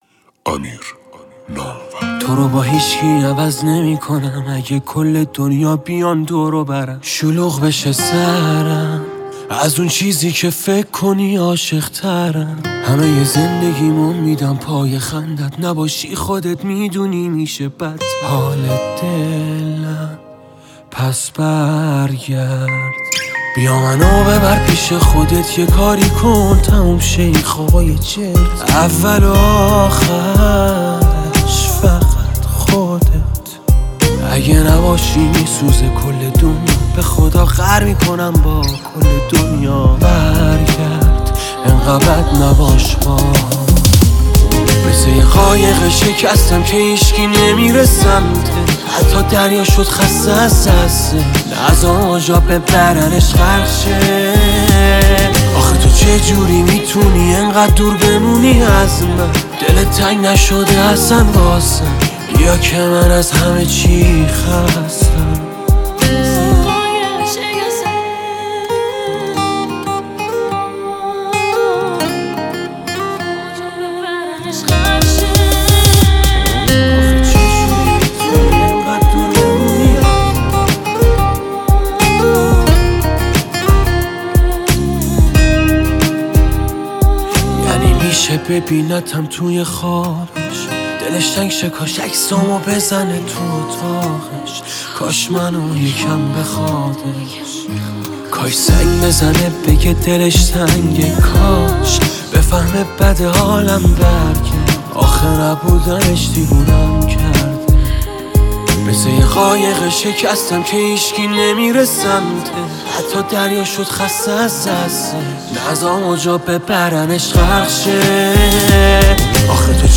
ترانه